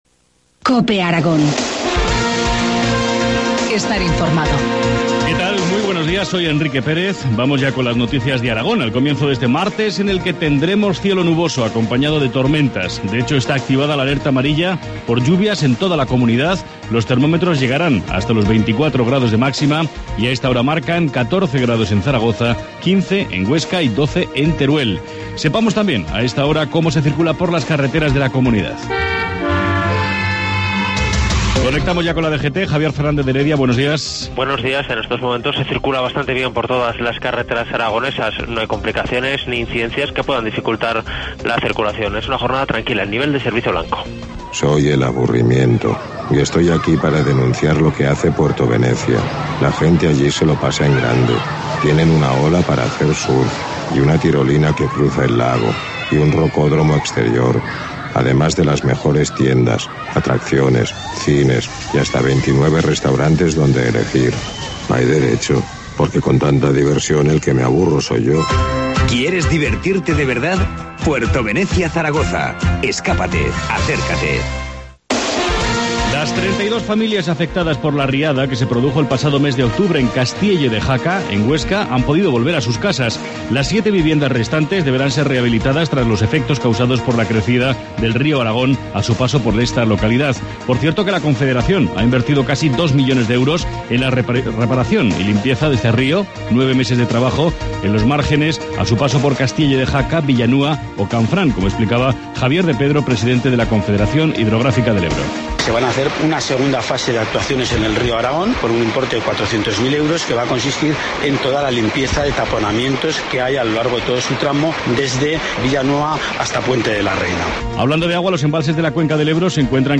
Informativo matinal, martes 14 de mayo, 7.25 horas